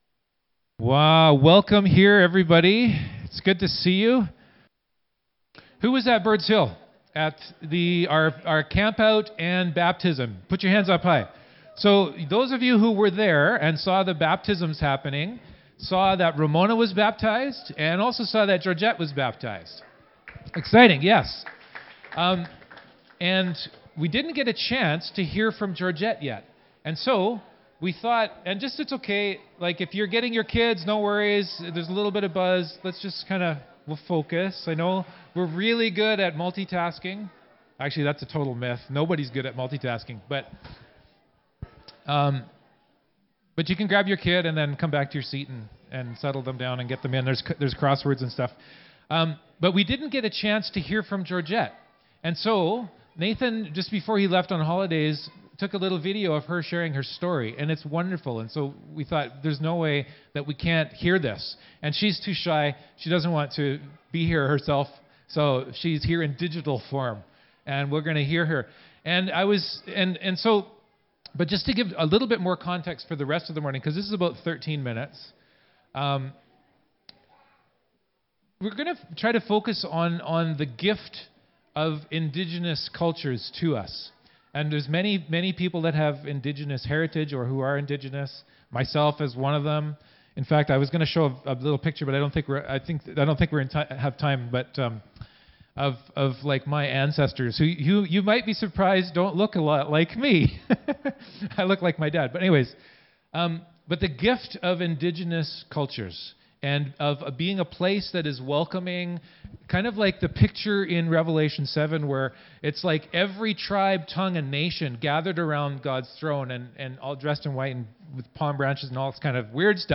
Service Type: Downstairs Gathering